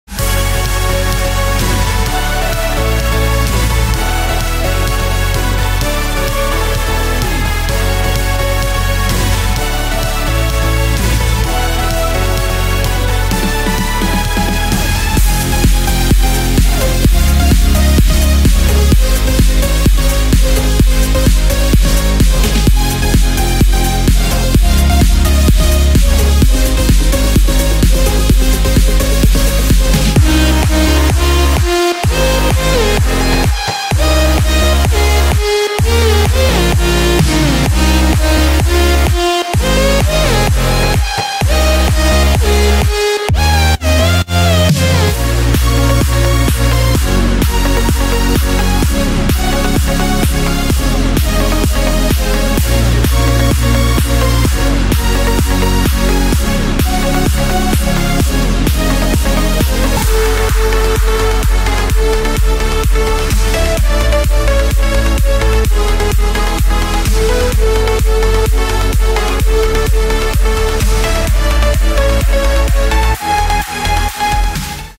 ➜ 125 One Shots (Kicks, Claps, Snares, Snaps, Hihats, Rides)
➜ 40 Drum Loops (Drop Claps, Drum Loops, Buildup Drums)